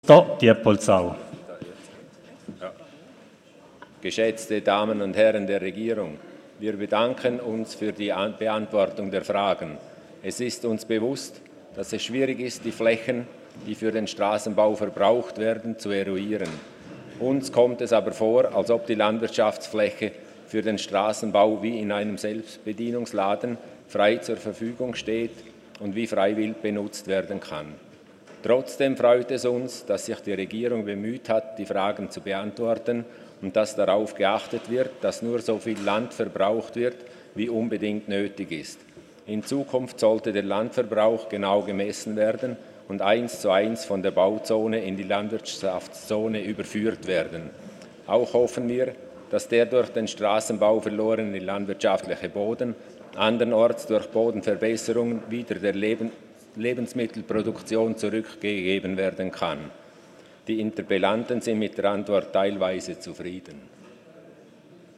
25.11.2019Wortmeldung
Sprecher: Kuster-Diepoldsau
Session des Kantonsrates vom 25. bis 27. November 2019